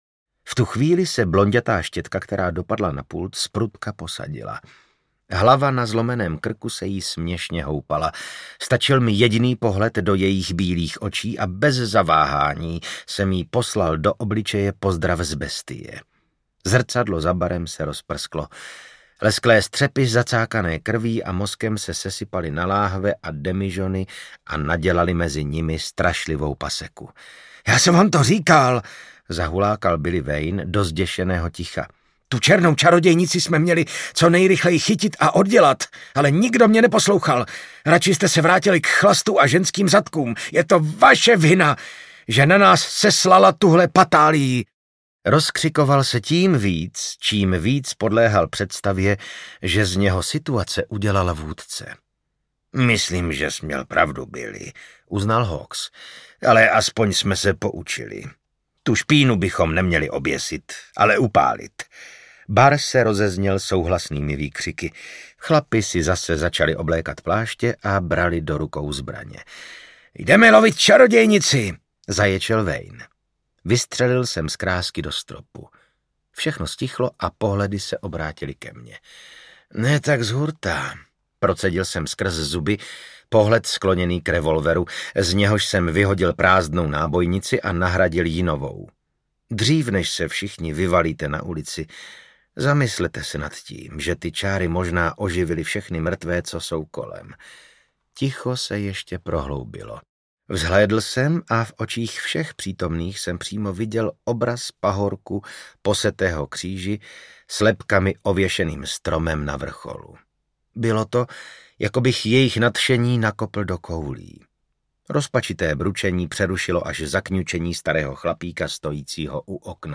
Chřestýš Callahan audiokniha
Ukázka z knihy
Vyrobilo studio Soundguru.
chrestys-callahan-audiokniha